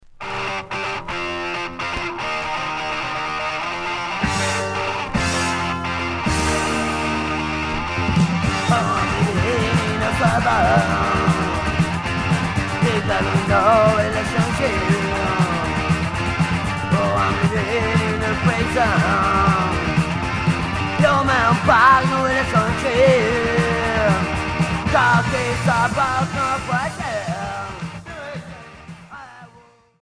Punk Premier 45t